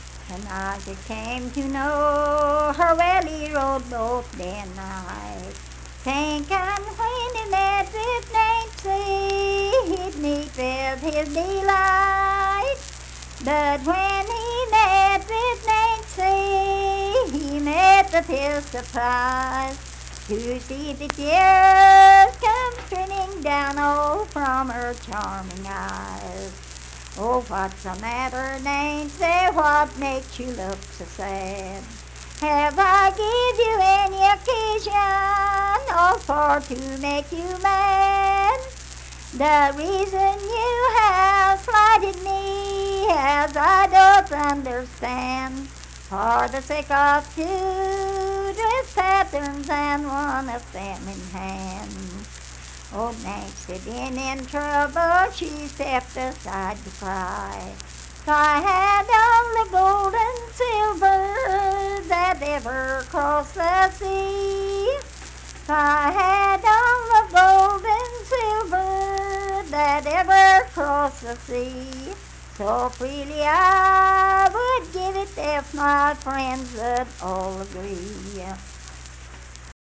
Unaccompanied vocal music performance
Voice (sung)
Logan County (W. Va.)